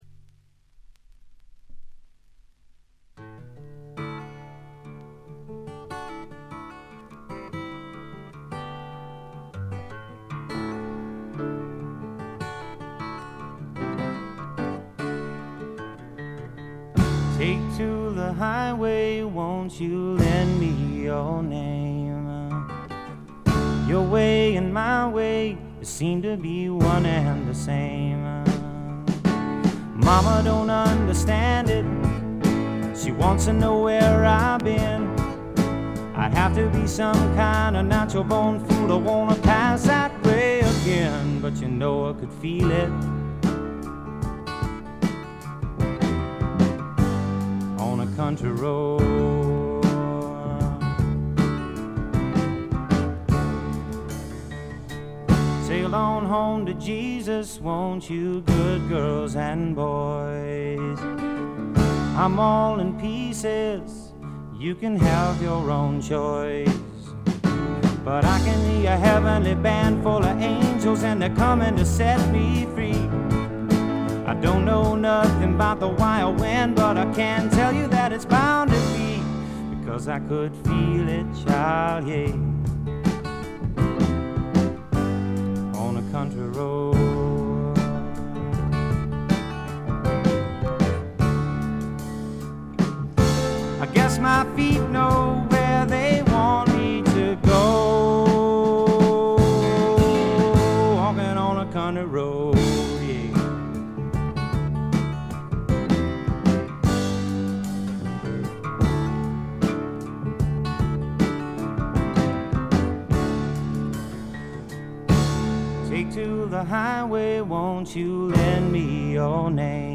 バックグラウンドノイズ、ところどころでチリプチ。
試聴曲は現品からの取り込み音源です。
Recorded at Sunset Sound, December '69